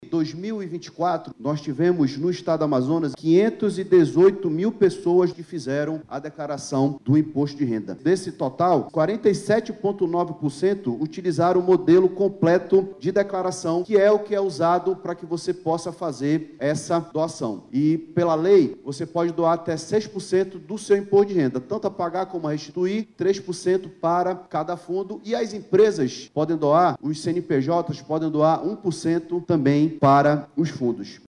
Ao destinar uma parte do Imposto de Renda, os cidadãos podem ajudar diretamente na melhoria da qualidade de vida de famílias em situação de vulnerabilidade social, ampliando a capacidade de projetos que atendem a essas pessoas, como explica o titular da Semasc, Saulo Viana.